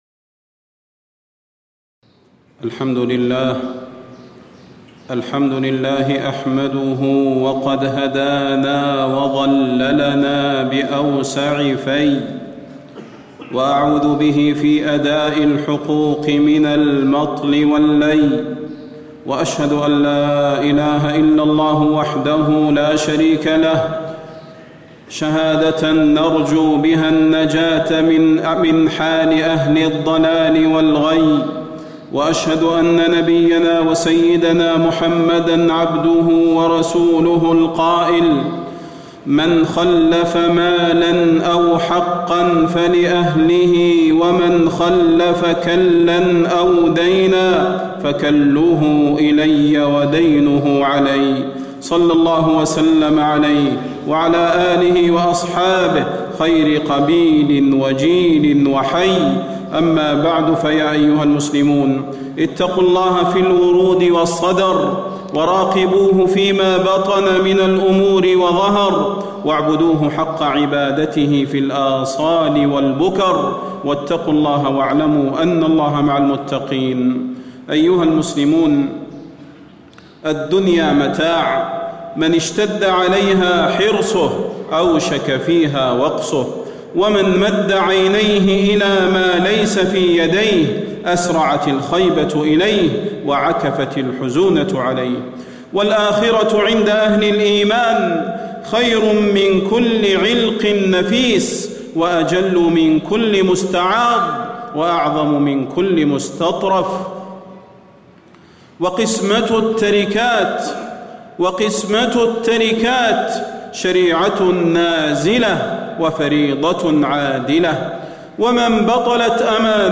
تاريخ النشر ٨ رجب ١٤٣٧ هـ المكان: المسجد النبوي الشيخ: فضيلة الشيخ د. صلاح بن محمد البدير فضيلة الشيخ د. صلاح بن محمد البدير خطورة الاعتداء على الميراث